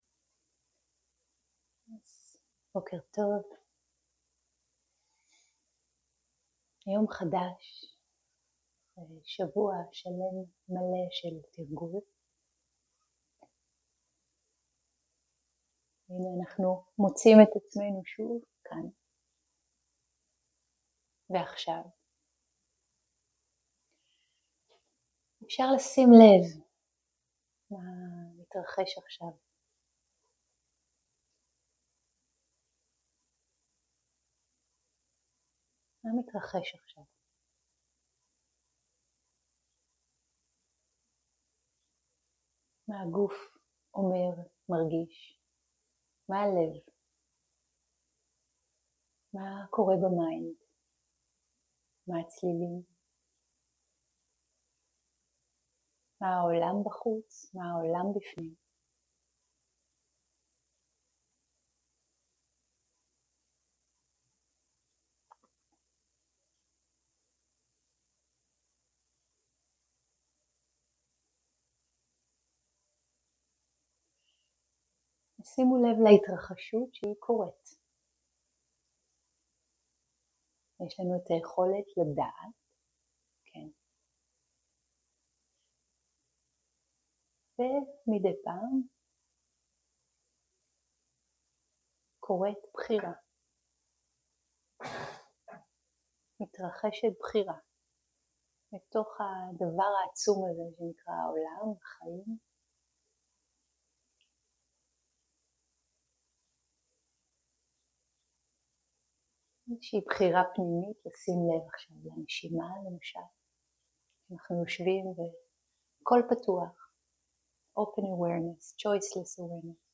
שיחת הנחיות - לא-עצמי - הבנת אשליית הבחירה החופשית + שאלות ותשובות
שיחת הנחיות - לא-עצמי - הבנת אשליית הבחירה החופשית + שאלות ותשובות 0:00 0:00 סוג ההקלטה: Dharma type: Guided meditation שפת ההקלטה: Dharma talk language: Hebrew